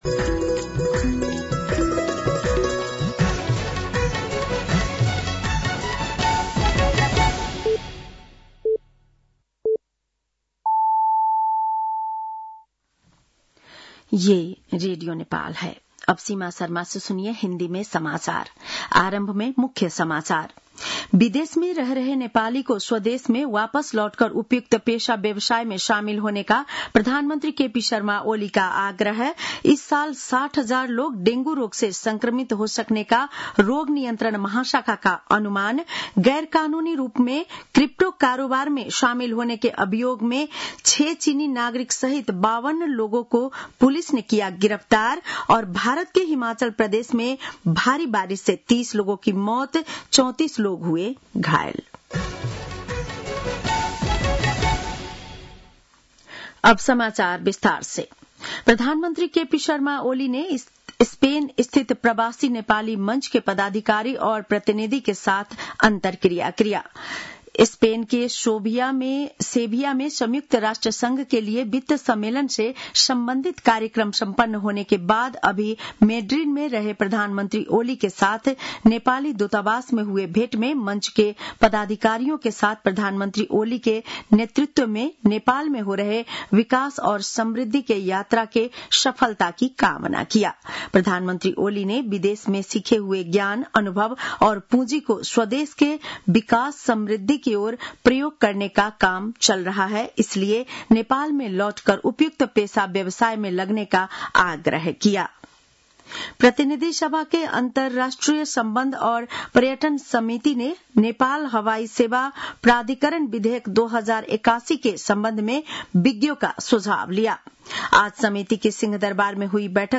बेलुकी १० बजेको हिन्दी समाचार : १९ असार , २०८२